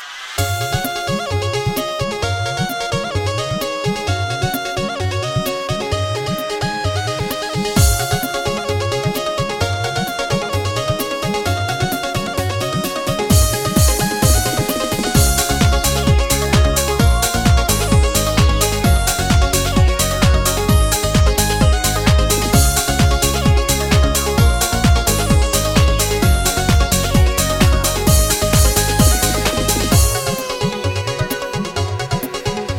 • Качество: 256, Stereo
инструментальные